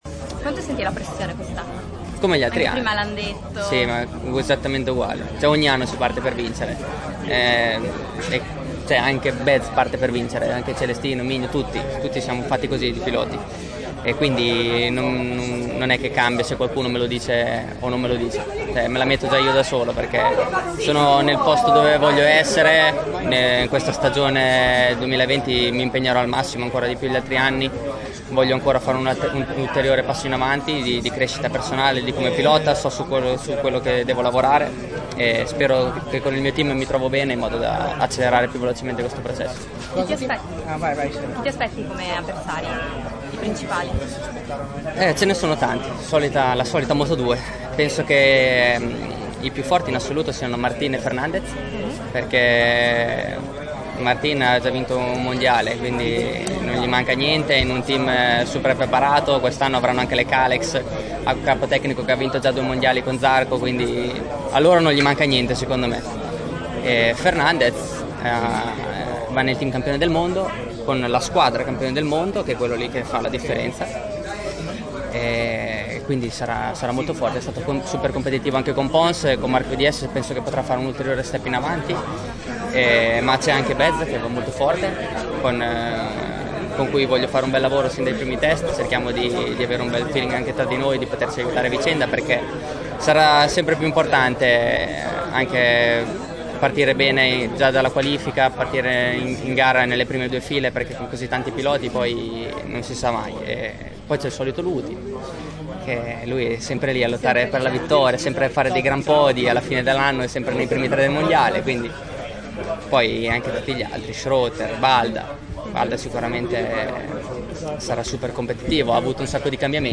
A Tavullia è tempo di presentazione per lo Sky Racing Team VR46, che corre nel motomondiale nelle classi Moto2 e Moto3.
Luca Marini , pilota Sky Racing Team Vr46, intervistato